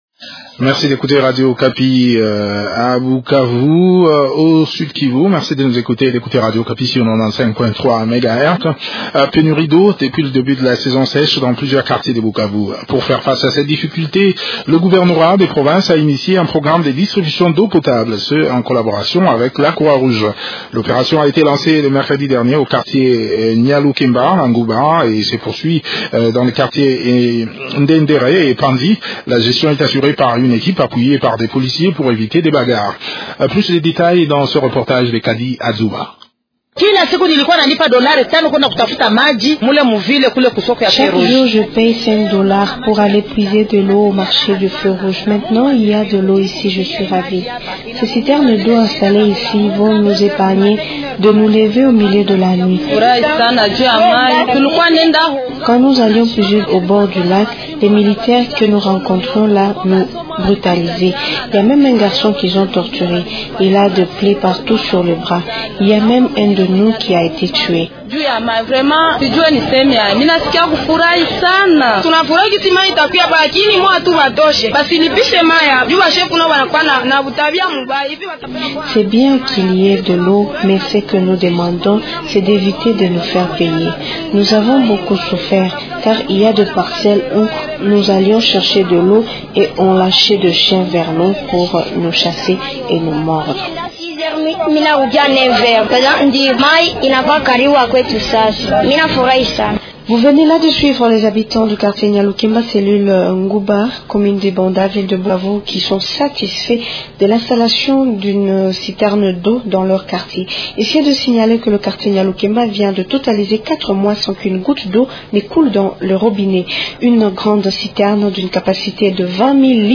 Invité : Timothée Masumbuko Kwalya, Ministre provincial de l’énergie.